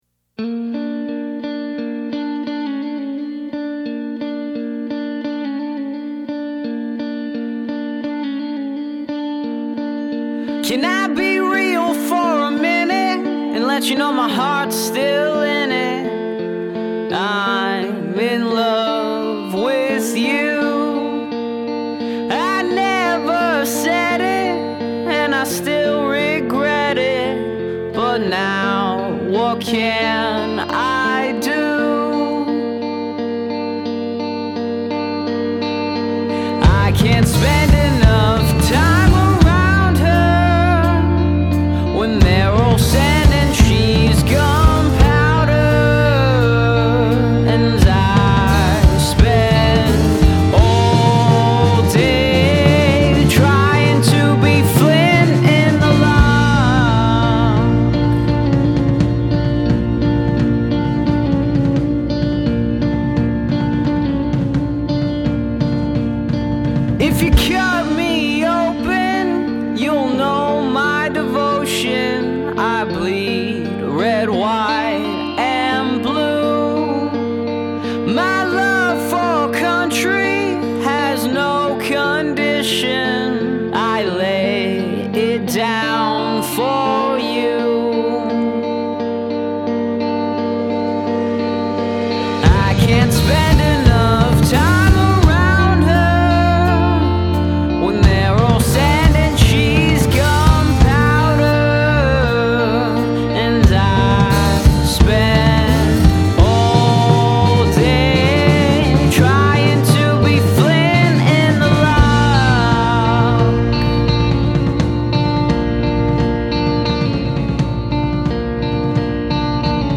L.A. indie-rock band
shows the sad, softer side